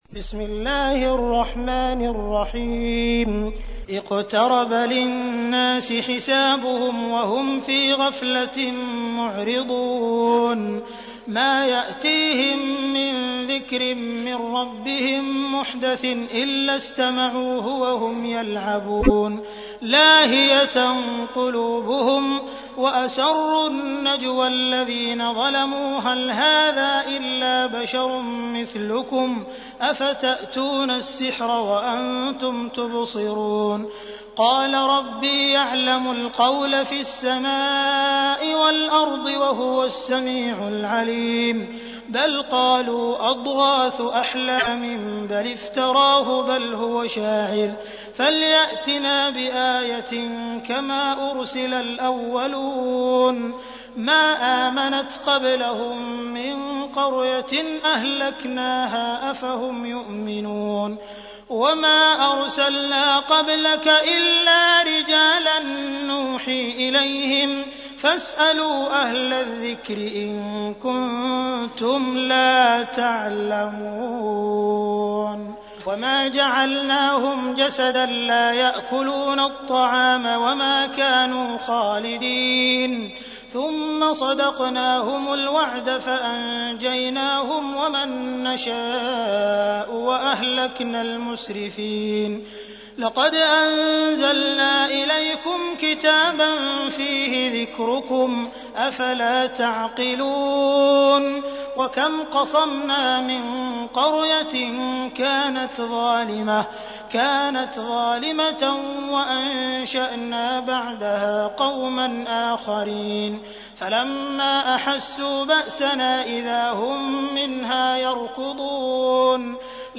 تحميل : 21. سورة الأنبياء / القارئ عبد الرحمن السديس / القرآن الكريم / موقع يا حسين